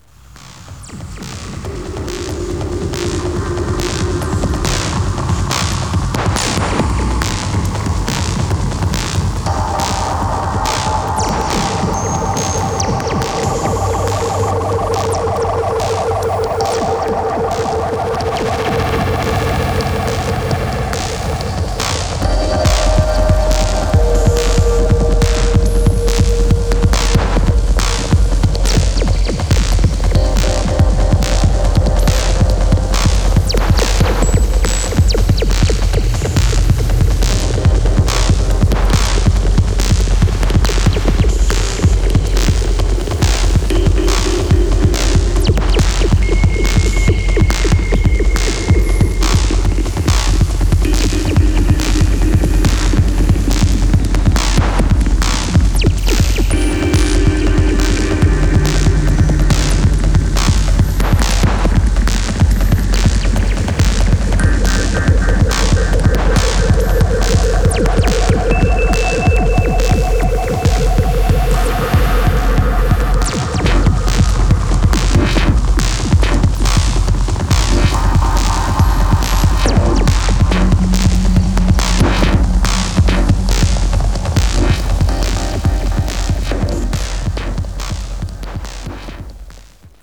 ハーフタイム、ポリリズムが錯綜、自動生成されてゆくような、テクノの何か得体の知れない領域が拡大しています。